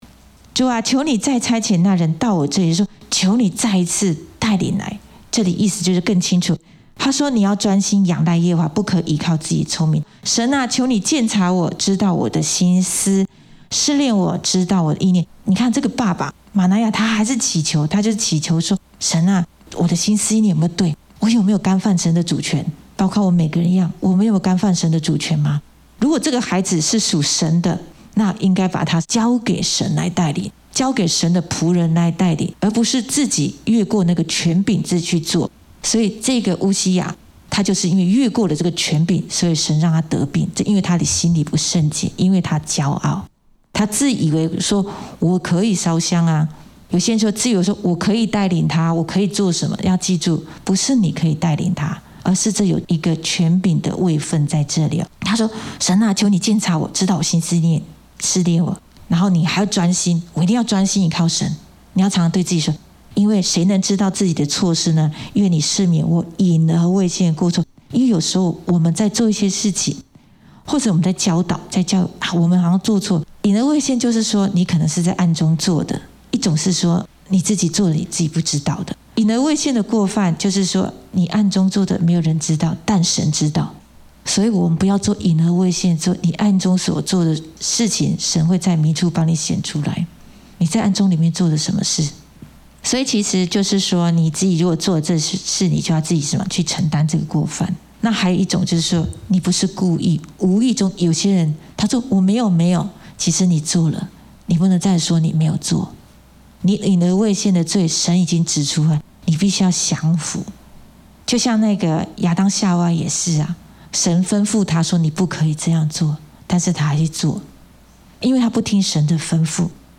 in 主日信息